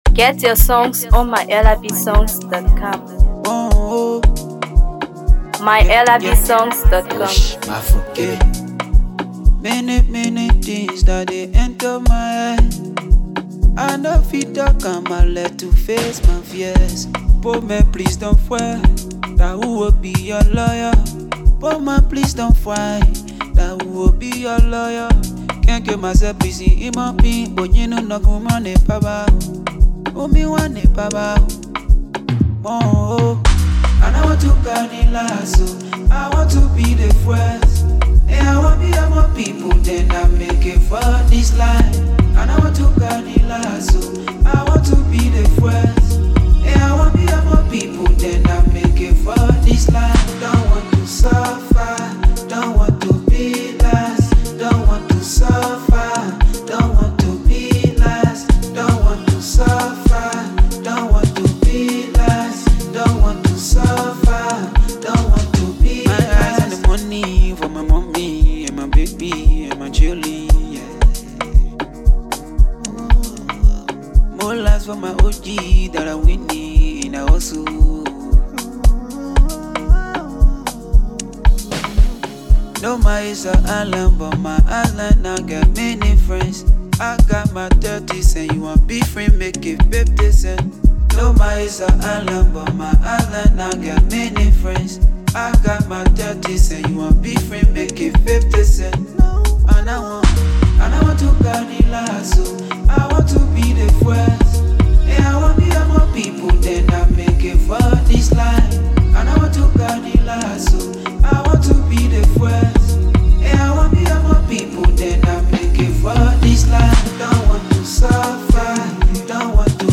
electrifying track
The raw emotion and motivational energy